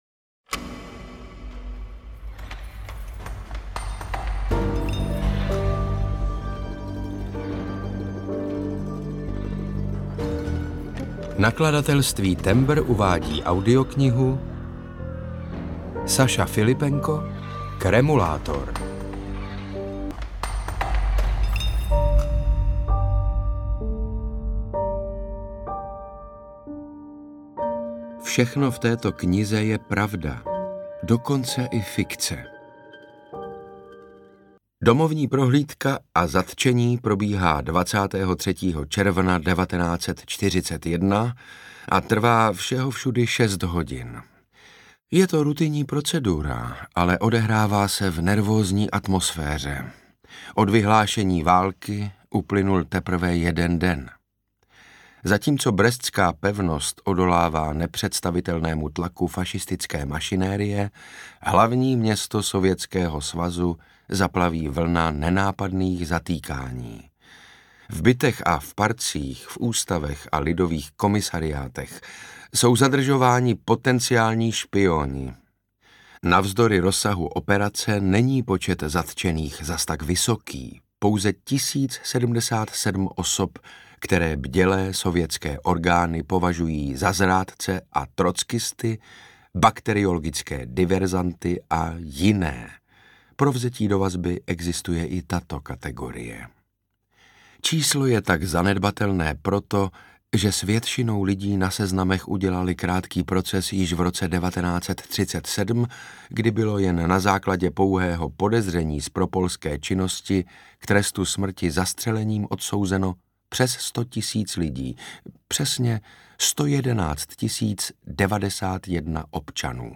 Kremulátor audiokniha
Ukázka z knihy